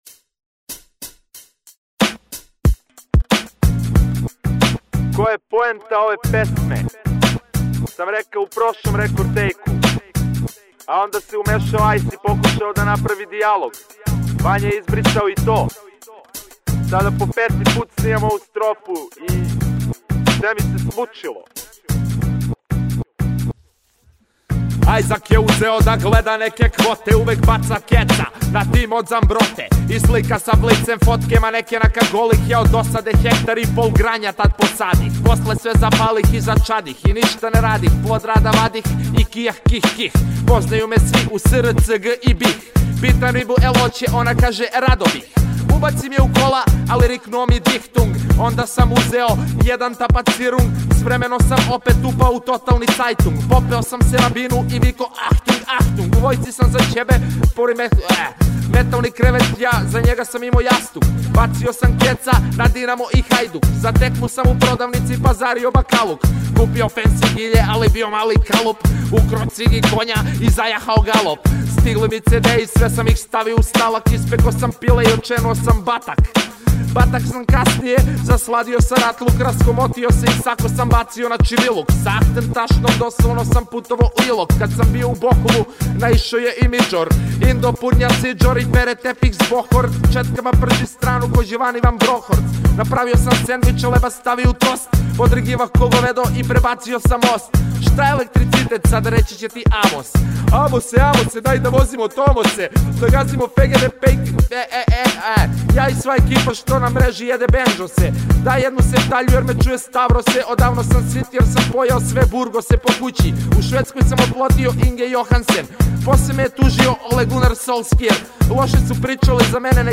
muzej (ne)objavljenih pesama domaćeg repa